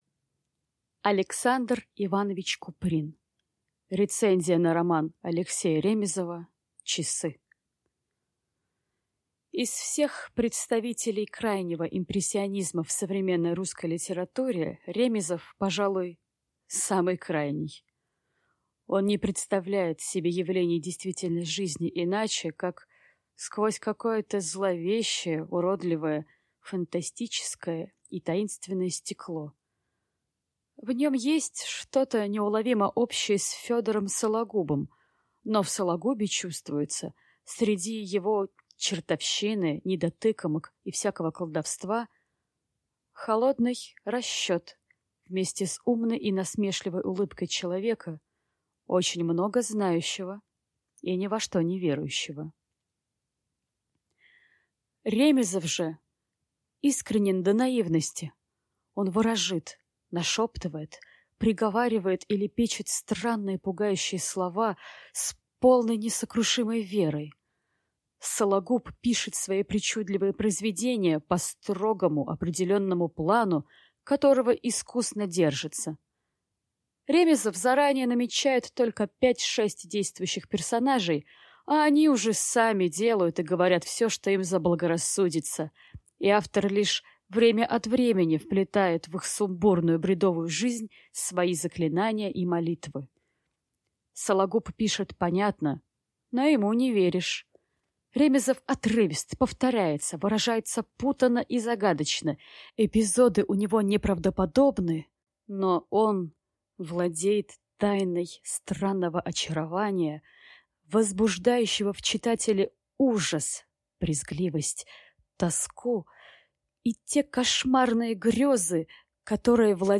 Аудиокнига Рецензия на роман А. Ремизова «Часы» | Библиотека аудиокниг